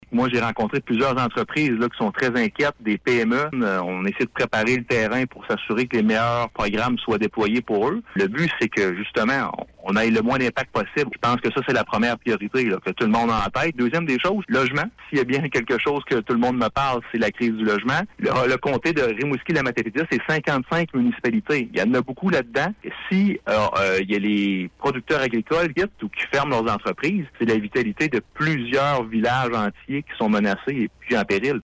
C’est ce qui ressort notamment d’une entrevue avec le candidat bloquiste réalisée par notre service des nouvelles, cette semaine.